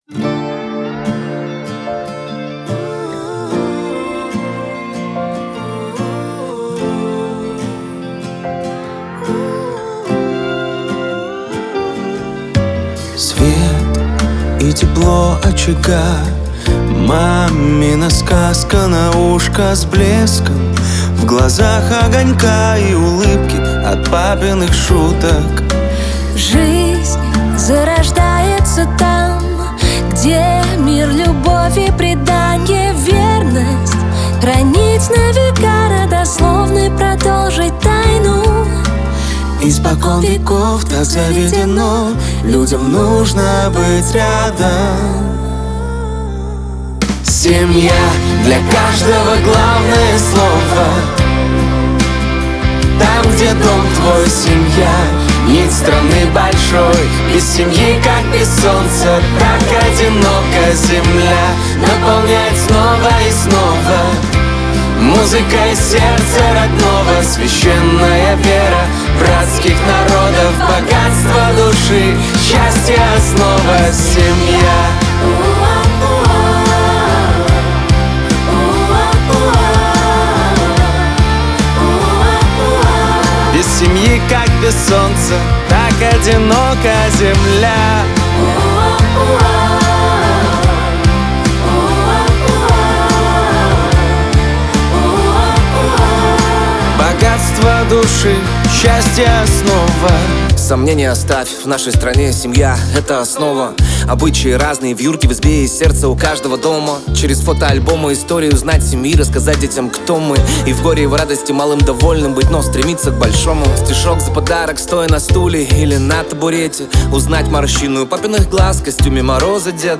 Качество: 320 kbps, stereo
песни для детишек